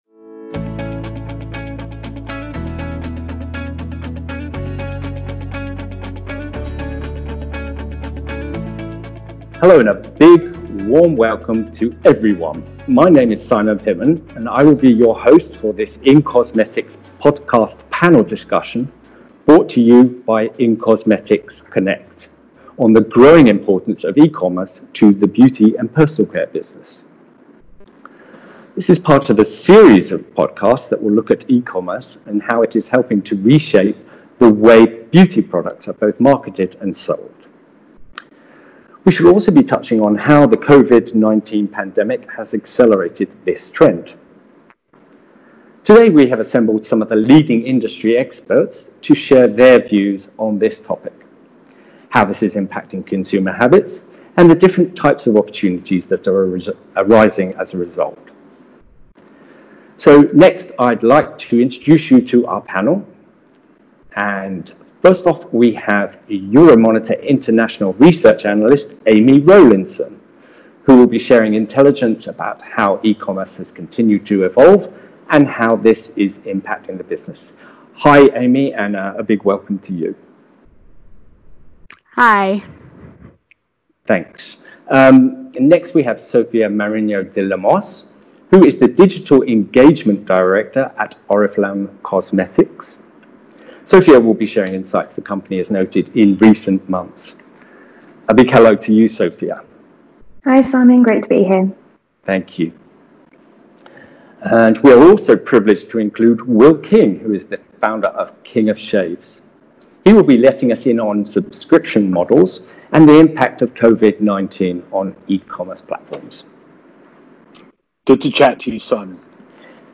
In the wake of the Covid-19 pandemic, consumer buying behaviour has evolved to a new normal resulting in a soar in beauty e-commerce. Listen to leading industry voices across the value chain as they discuss the impact and different opportunities arising across from this consumer shift to online retail.